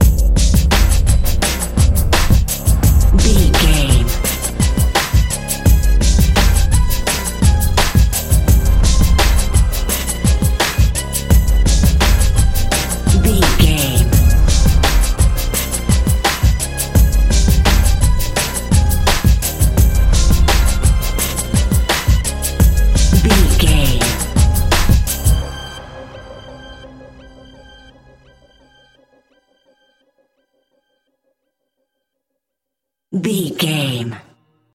Aeolian/Minor
D
drum machine
synthesiser
hip hop
Funk
neo soul
acid jazz
energetic
bouncy
funky
hard hitting